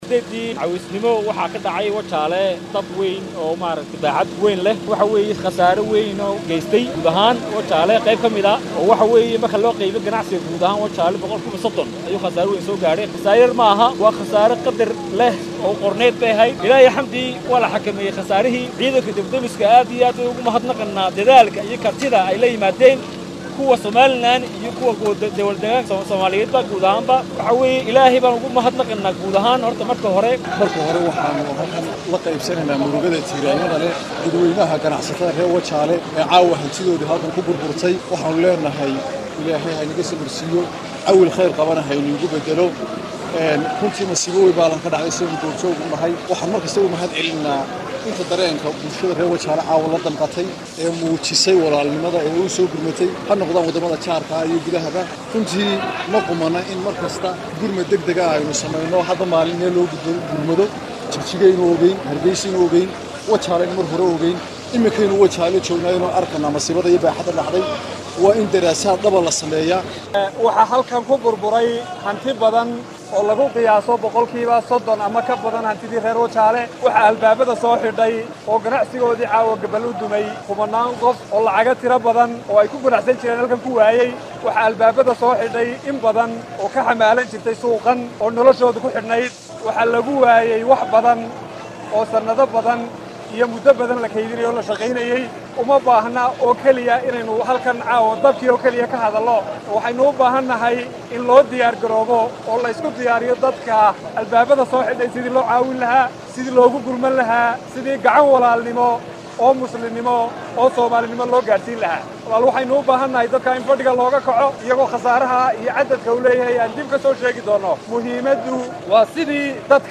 Dhanka kale, mas’uuliyiinta degmada Tog Wajaale ayaa ka hadlay khasaaraha uu dabka gaystay.
Masuuliyiinta-Tog-Wajaale.mp3